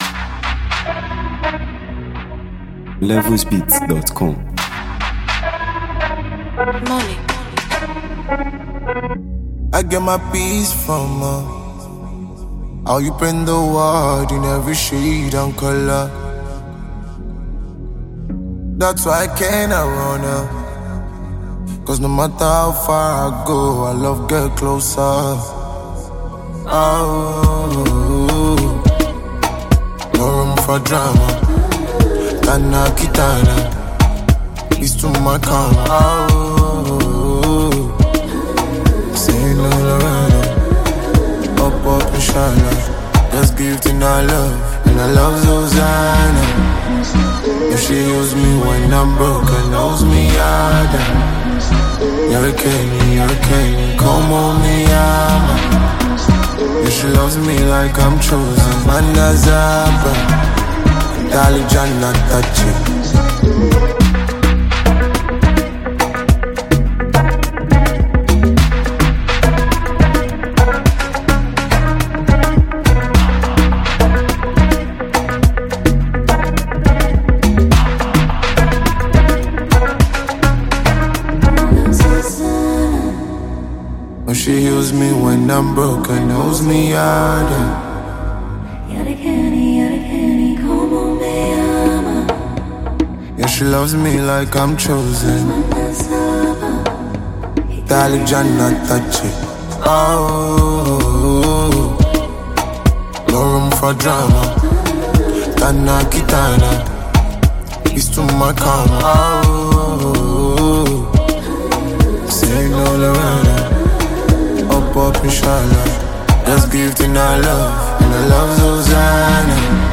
soulful vocal delivery
With its infectious rhythm and relatable message